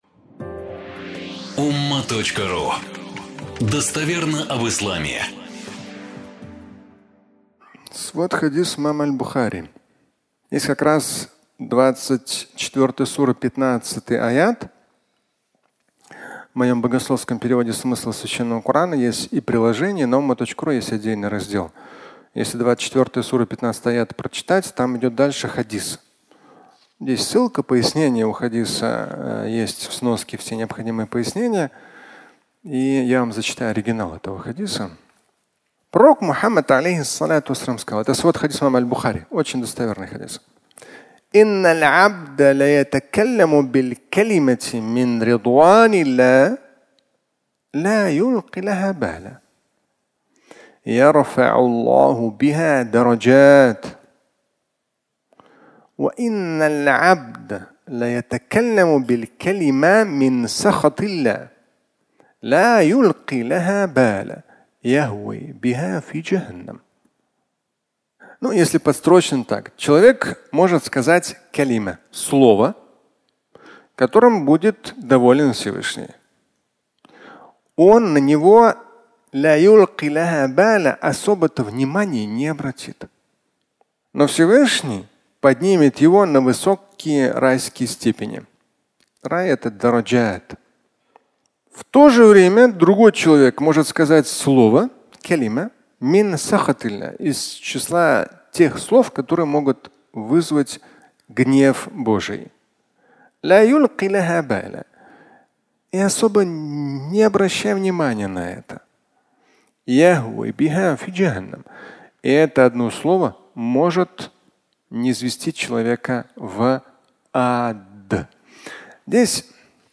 Одно слово (аудиолекция)